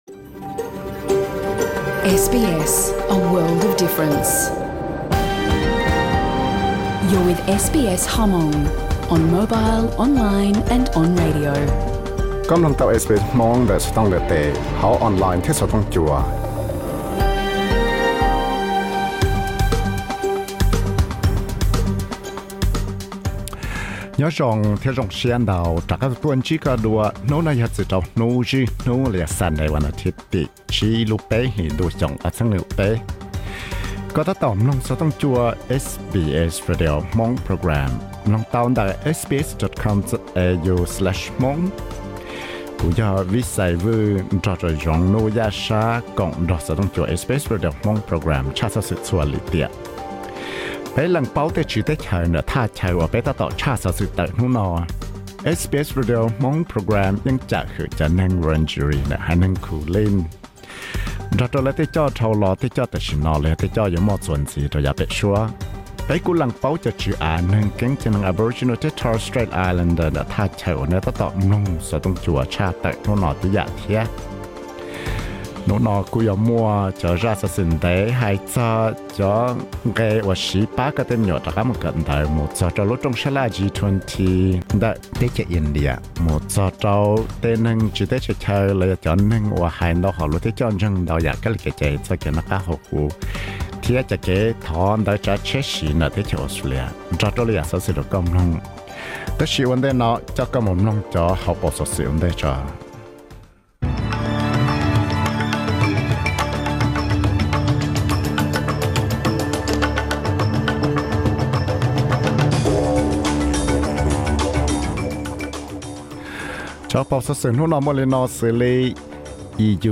Xov xwm hnub zwj Hnub (Sunday news 05.03.2023), nqe pab me nyuam rov qab mus kawm ntawv, rooj sab laj G-20 ntawm India.